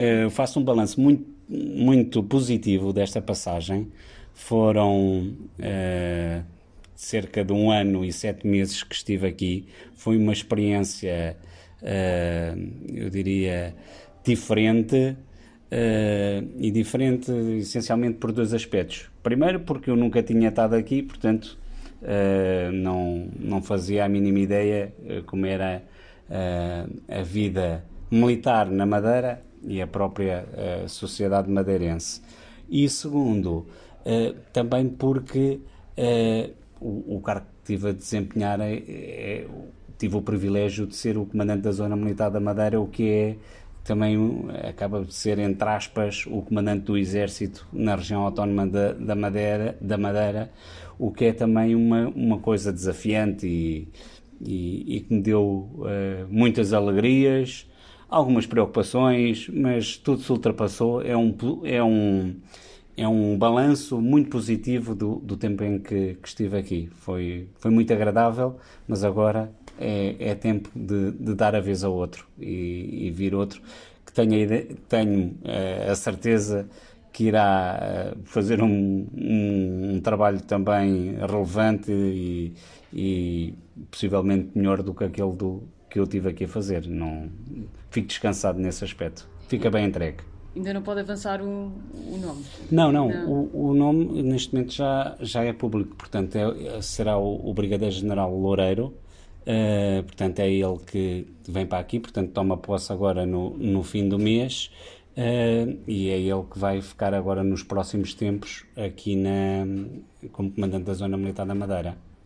O Secretário Regional da Economia, José Manuel Rodrigues, recebeu, esta terça-feira, em audiência para apresentação de cumprimentos de despedida, o Comandante da Zona Militar da Madeira, Major-General Jorge Pedro, que cessará funções no final de julho.